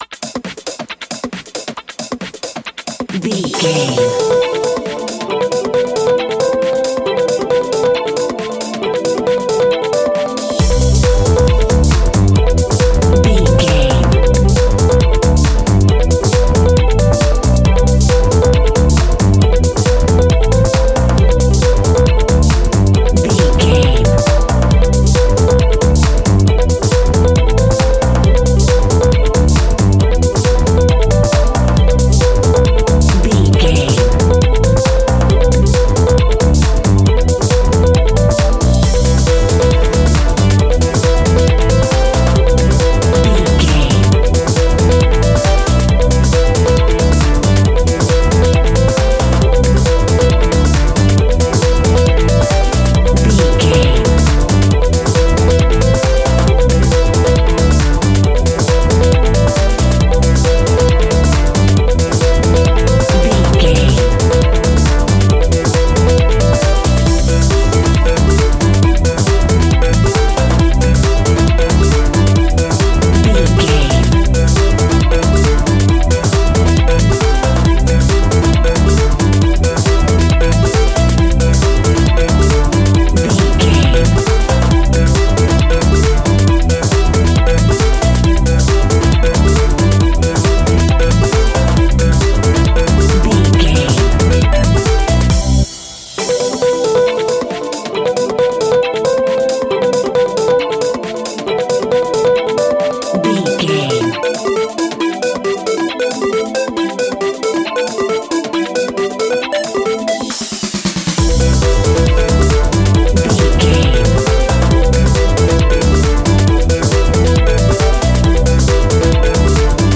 Phrygian
fun
uplifting
futuristic
driving
energetic
repetitive
bouncy
bass guitar
synthesiser
electric piano
drum machine
funky house
electronic funk
upbeat
synth drums
synth bass
synth lead
Synth Pads
clavinet
horns